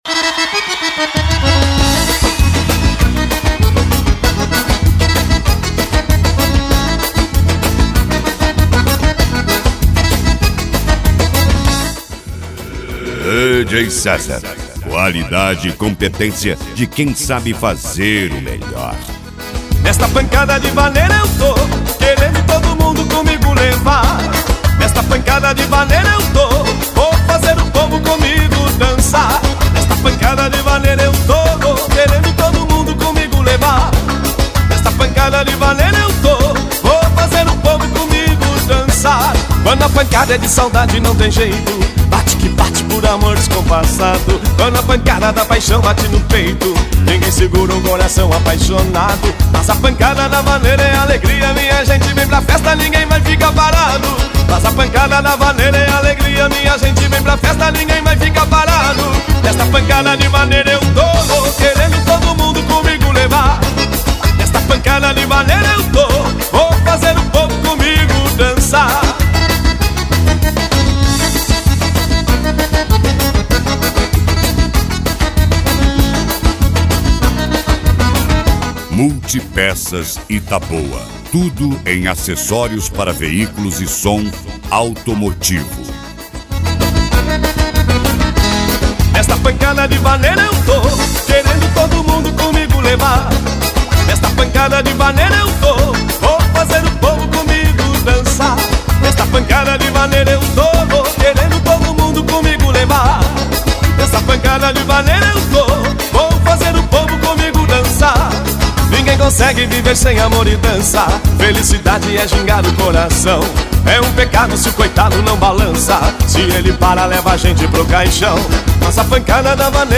Moda de Viola
Modao
SERTANEJO
Sertanejo Raiz
Sertanejo Universitario